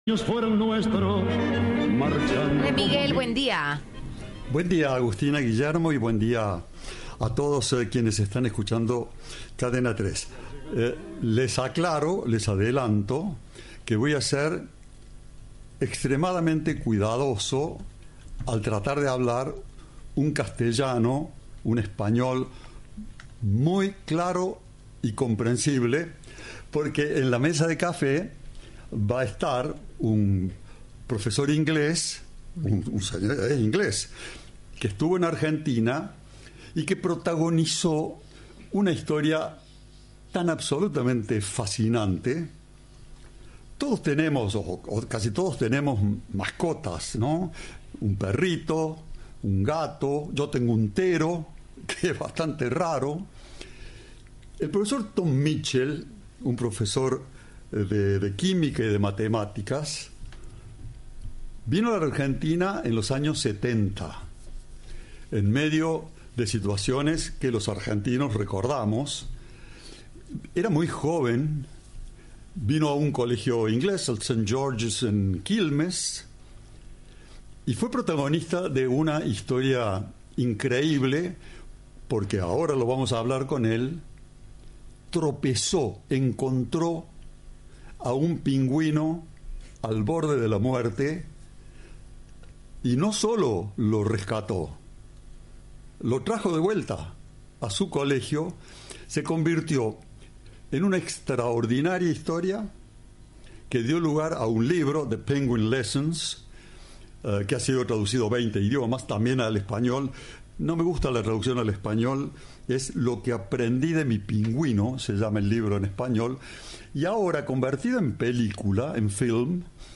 En La Casa del Tango